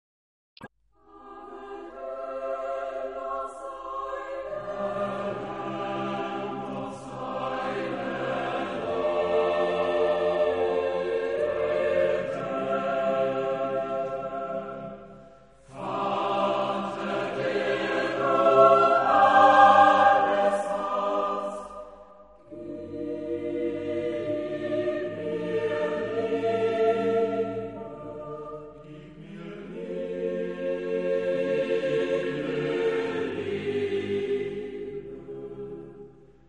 Genre-Stil-Form: weltlich ; romantisch
Chorgattung: SSAATTBB  (8 gemischter Chor Stimmen )